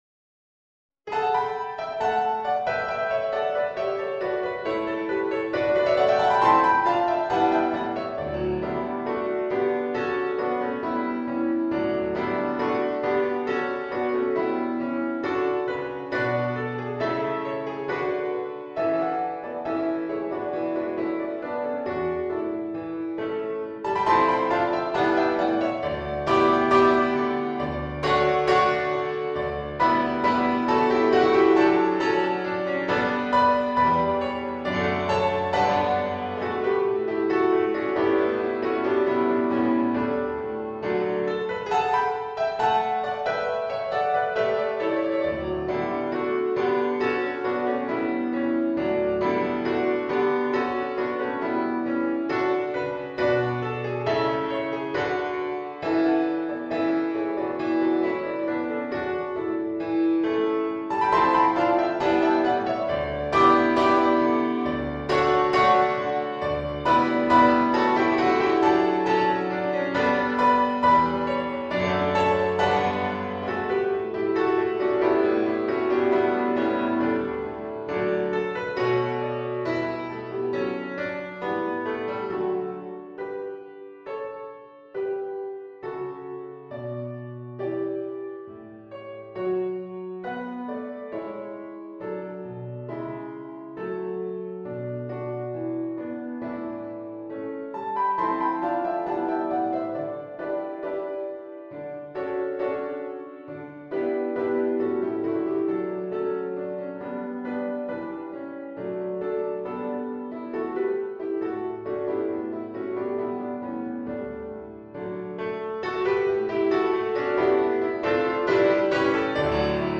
Star-Carol-Backing.mp3